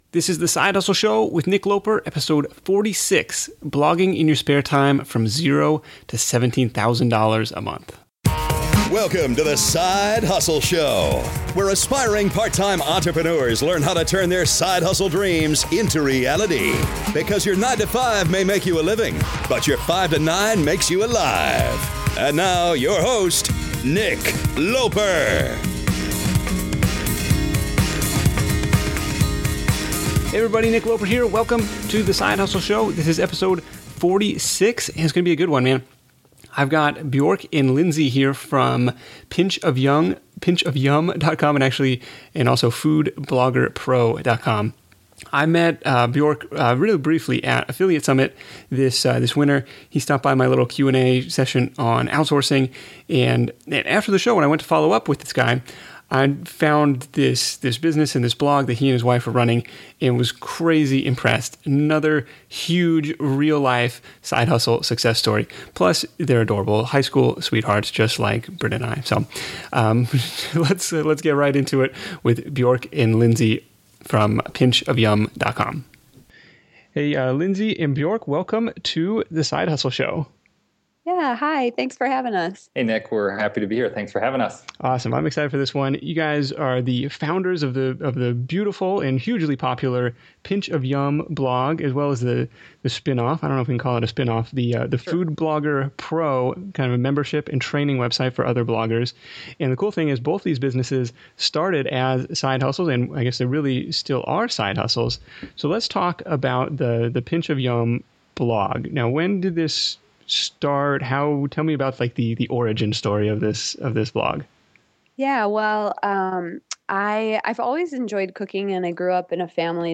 In this interview we dive into how they got started, how the readership grew, and how they monetize the site.